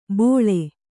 ♪ bōḷe